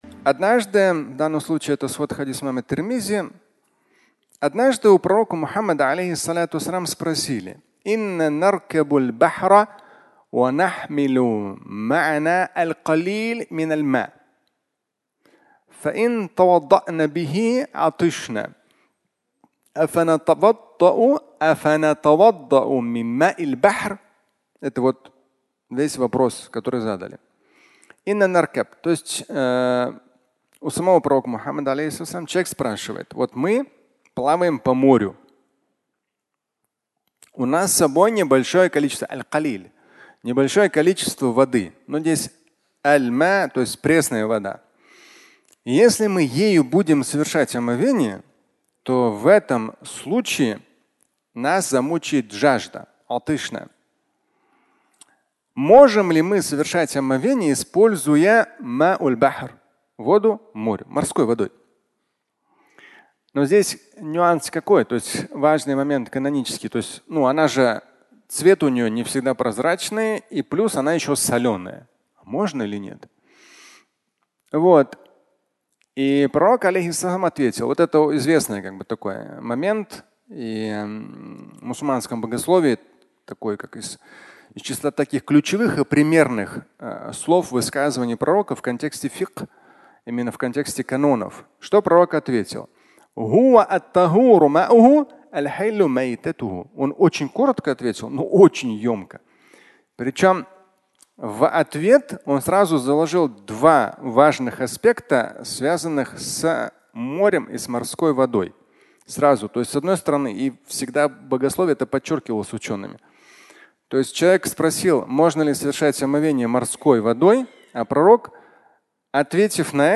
«Умерло в море» (аудиолекция)
Фрагмент пятничной лекции